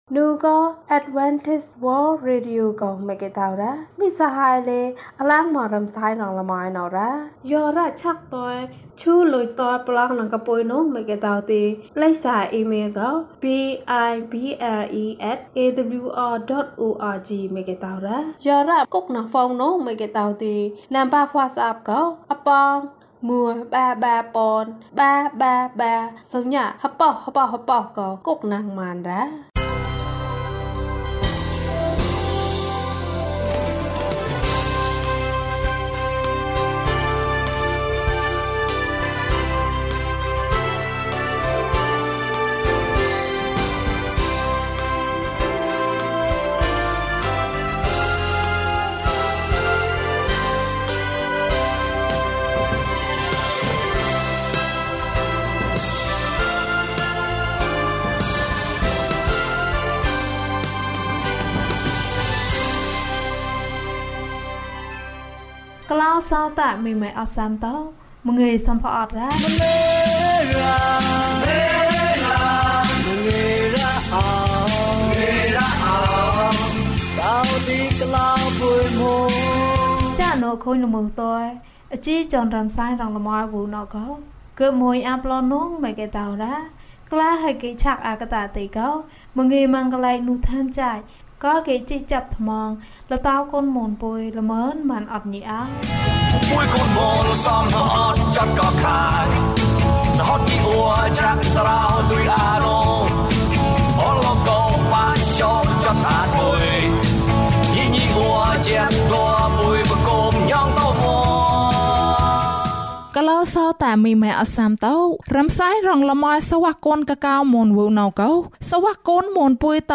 သင့်သားသမီးများကို ယေရှု၏အလိုဆန္ဒဖြစ်ကြောင်း သွန်သင်ပါ။ ကျန်းမာခြင်းအကြောင်းအရာ။ ဓမ္မသီချင်း။ တရားဒေသနာ။